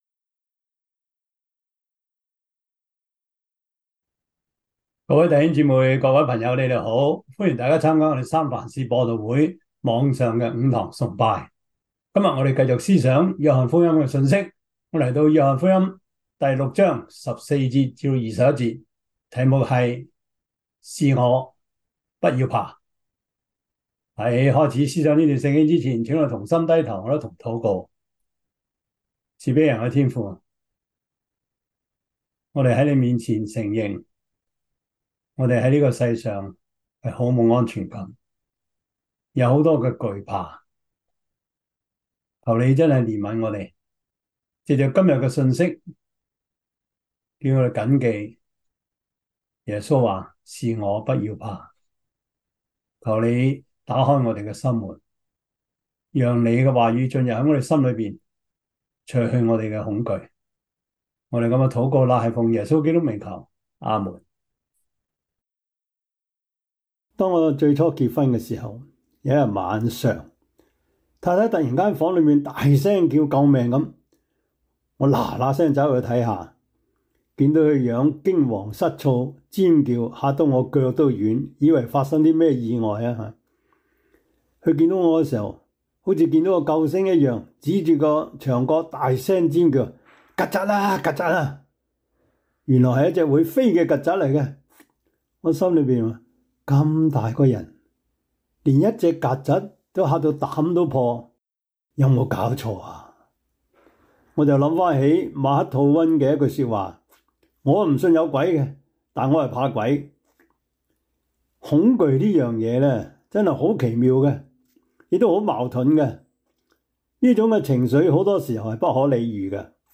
約翰福音 6:14-21 Service Type: 主日崇拜 約翰福音 6:14-21 Chinese Union Version